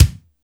Index of /90_sSampleCDs/Roland L-CDX-01/KIT_Drum Kits 1/KIT_LA Fat Kit 1
KIK TV KIK 2.wav